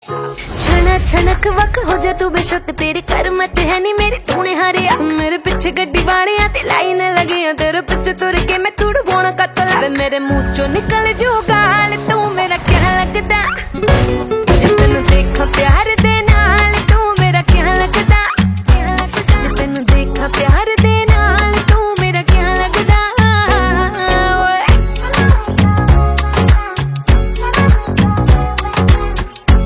melodious